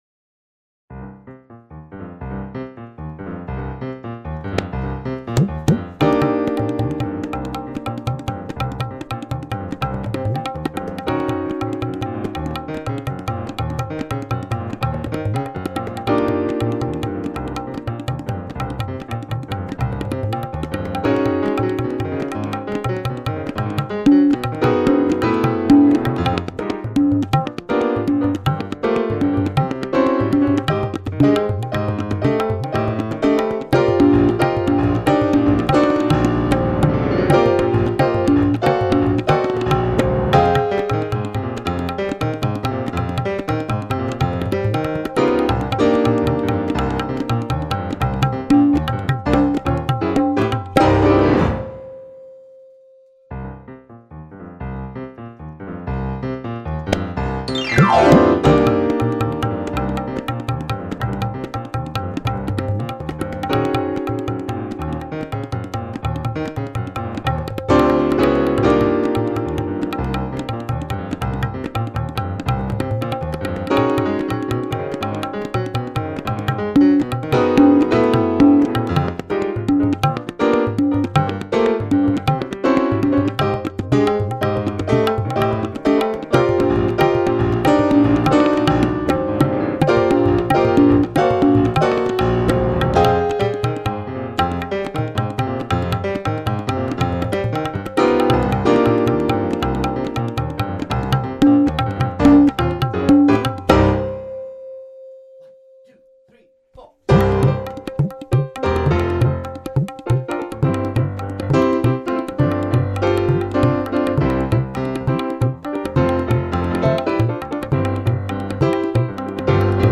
South African jazz piece
Type: Studio Recording Performers
piano
tabla) Location: Gottmadingen Source